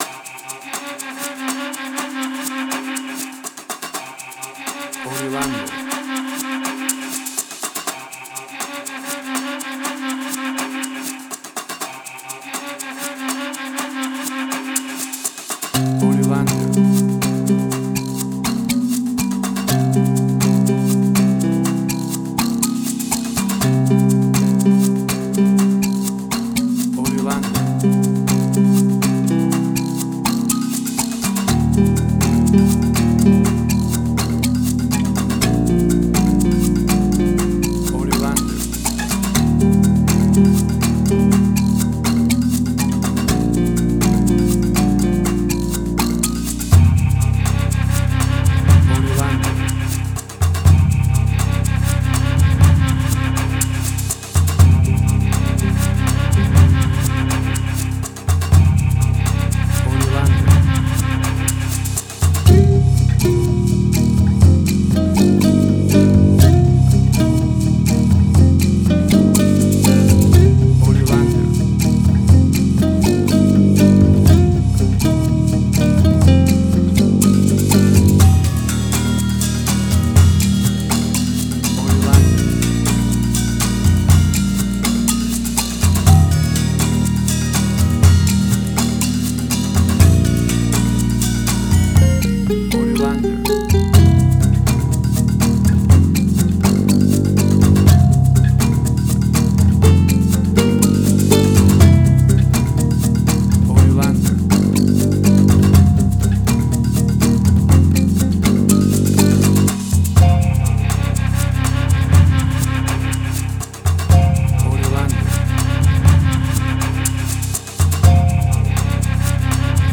World Fusion_Similar_BBC Documentaries.
Tempo (BPM): 122